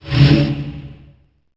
1 channel
Percu13C.wav